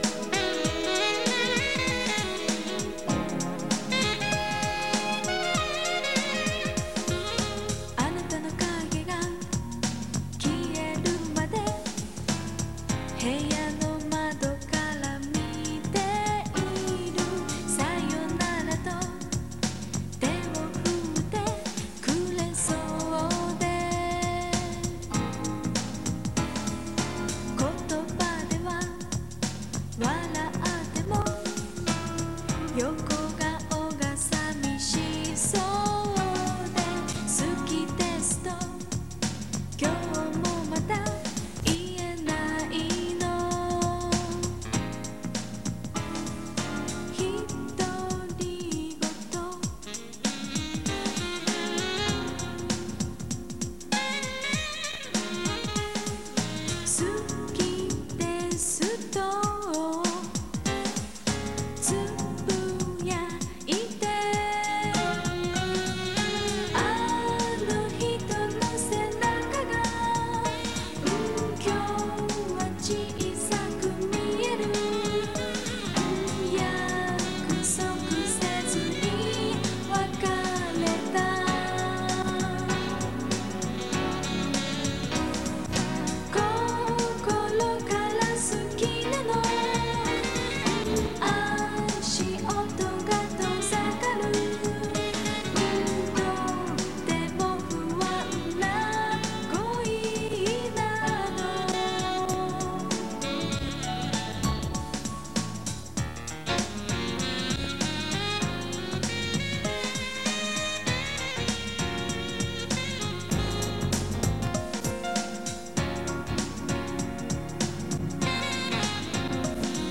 색소폰 사운드가
J-Pop
2007-05-26 오후 2:23:00 색소폰 연주가 인상적이고 전체적인 분위기가 신선하네요.